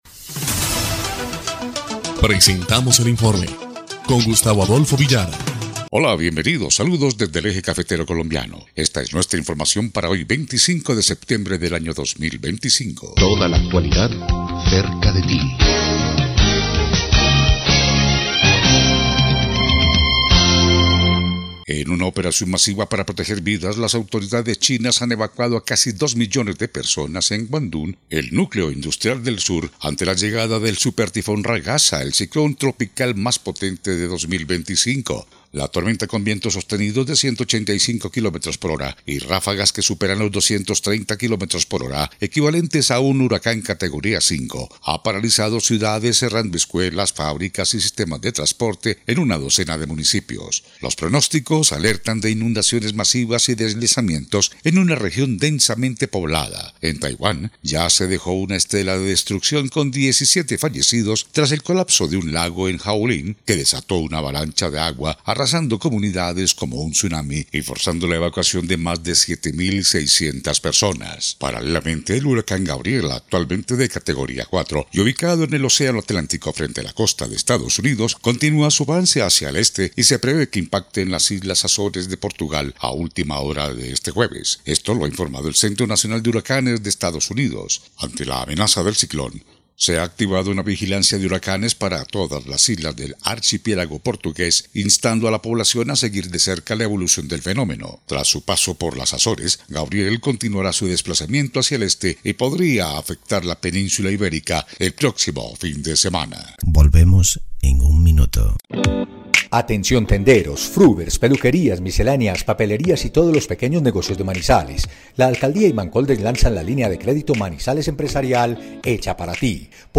EL INFORME 2° Clip de Noticias del 25 de septiembre de 2025